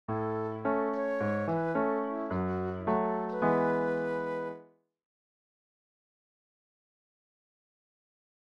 To give you an idea of the effect we’re talking about, here are some simple short melodic fragments, first given in a major key, and then in a minor key equivalent:
A  A/G#  F#m  E (
These are just midi files that I’ve posted here, so you’ll have to use your imagination and your own instrumentation to bring them to life.